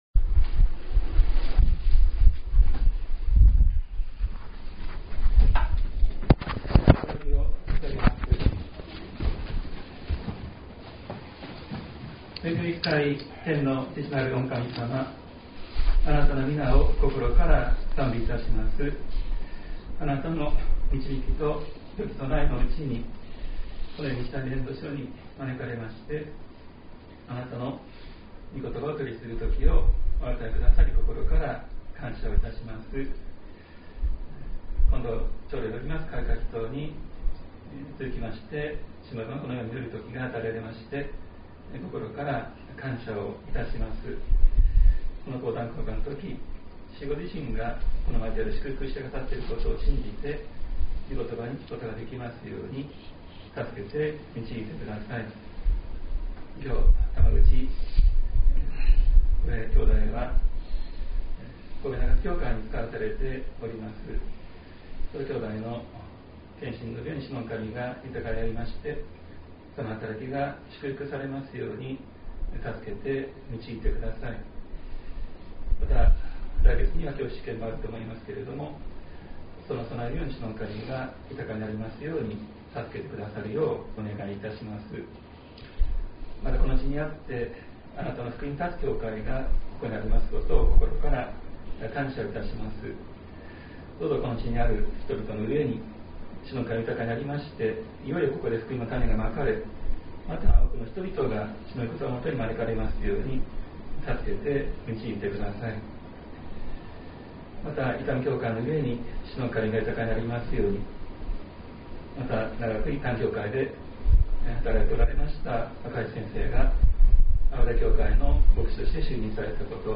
2024年06月23日朝の礼拝「ただ一つのいけにえ」西谷教会
音声ファイル 礼拝説教を録音した音声ファイルを公開しています。